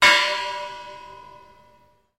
Звуки металла, ударов
Вот молотком ударили